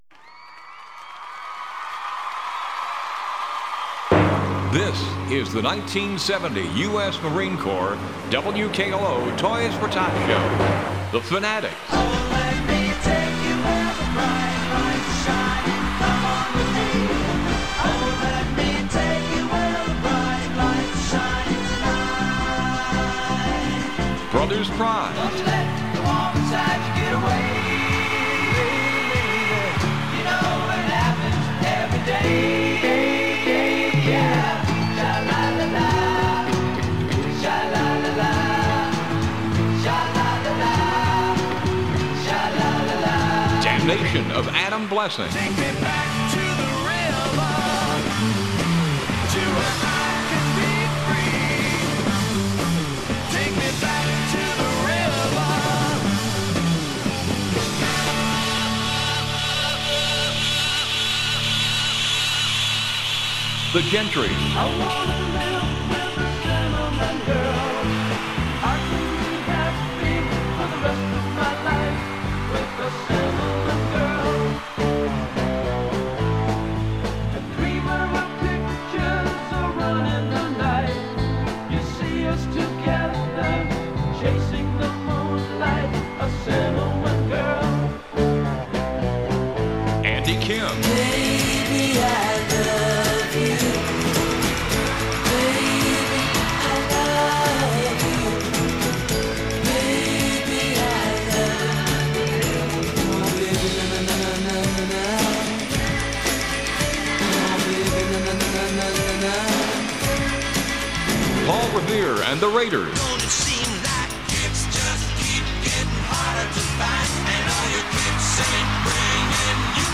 WKLO 1970 Toys for Tots Audio Collage